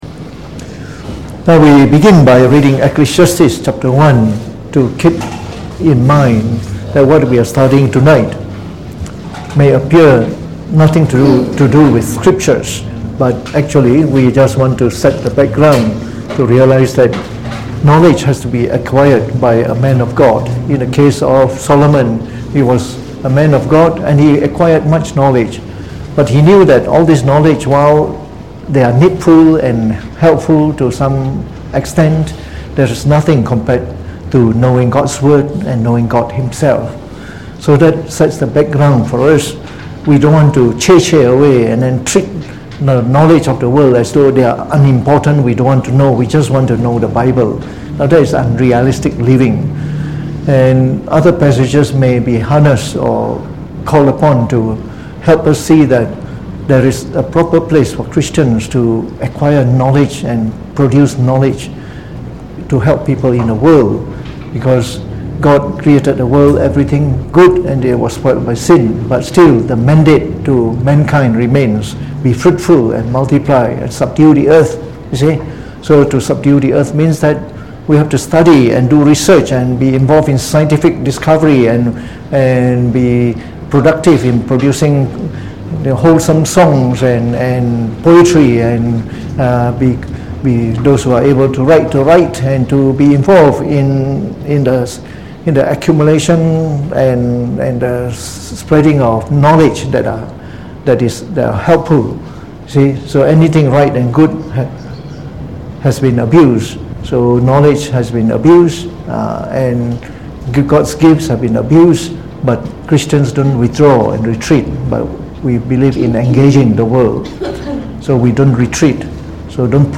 Delivered on the 18th of September 2019 during the Bible Study, from the series on The Chinese Religion.